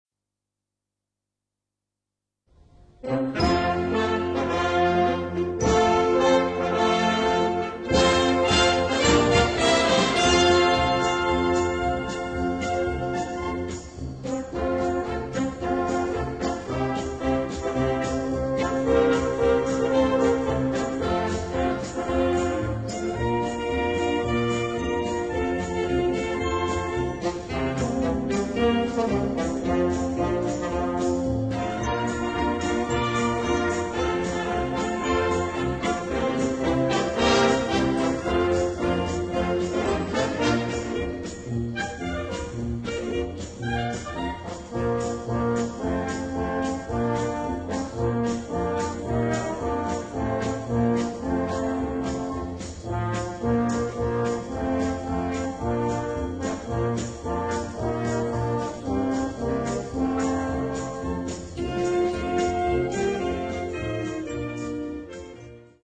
Gattung: Volkslieder-Medley
Besetzung: Blasorchester
im "Happy-Sound".